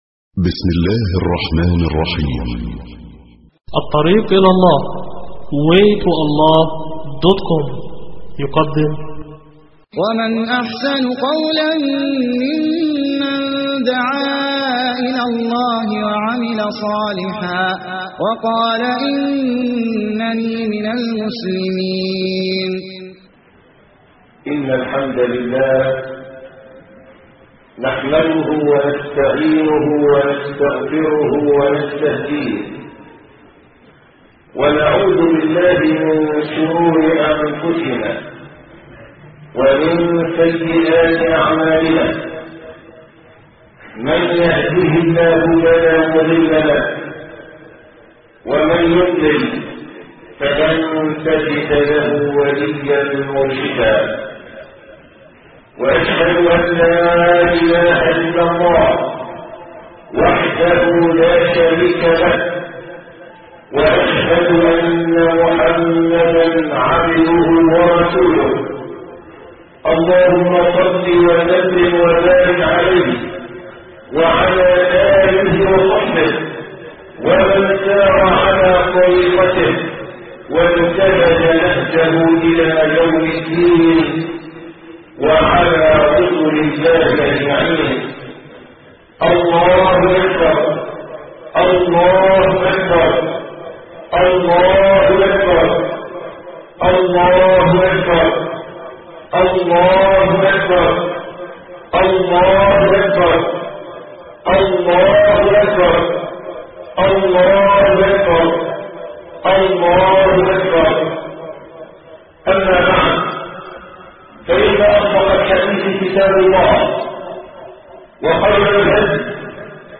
خطبة عيد الأضحي 1430هـ